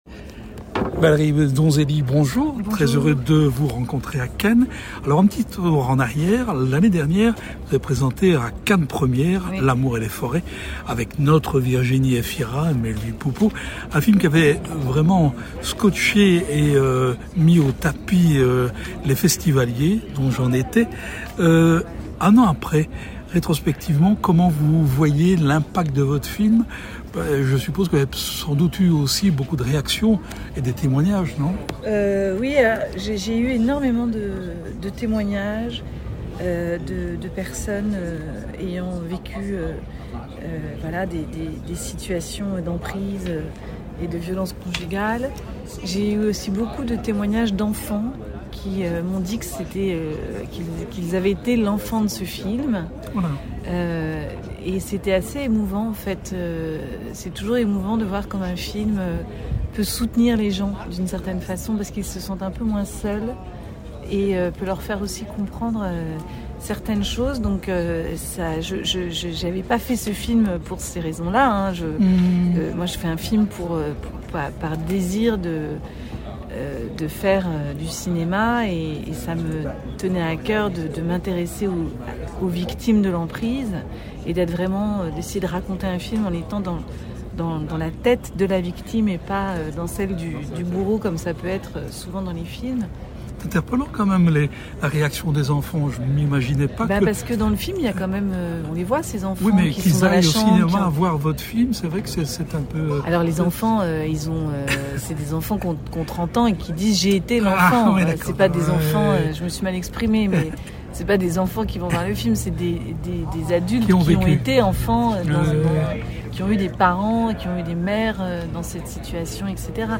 Elle préside aussi le Jury du Prix de la Citoyenneté 2024 entourée notamment de Frédéric Sojcher, Claus Drexel, Agathe Bonitzer… Rencontre.